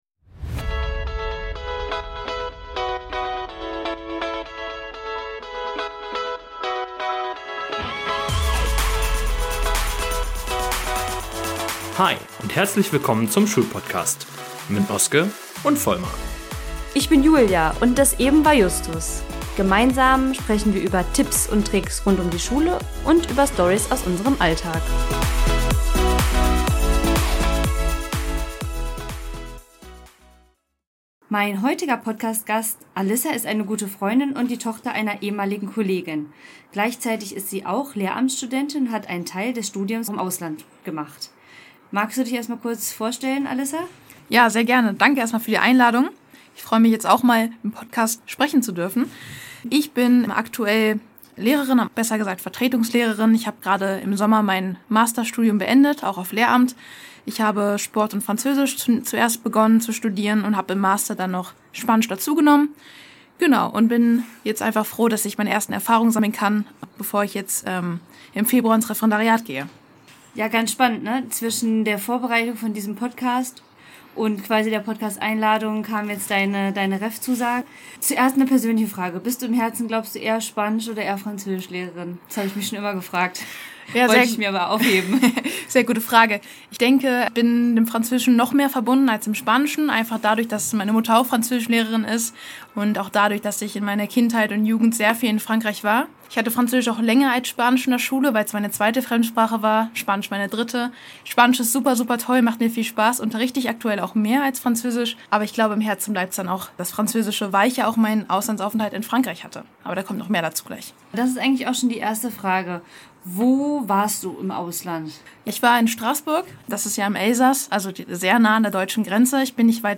59-interview-im-lehramtsstudium-ins-ausland-das-erwartet-dich.mp3